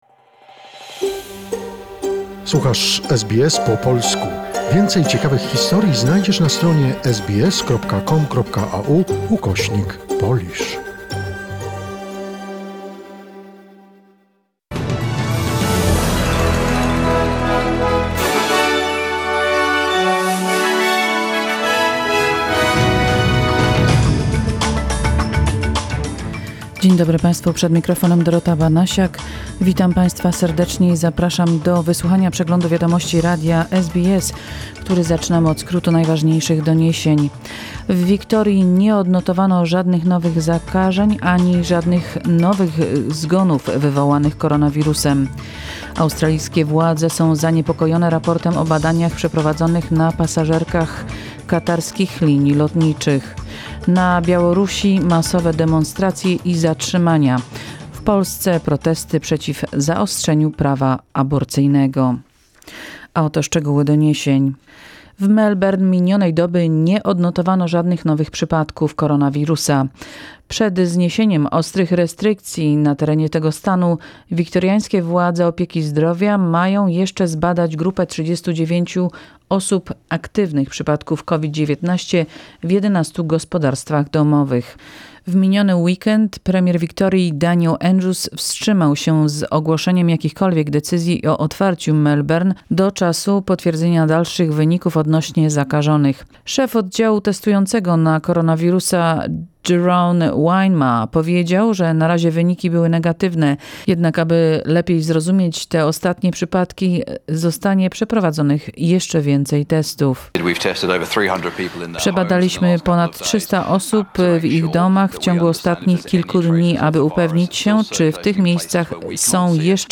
SBS News, 26 October 2020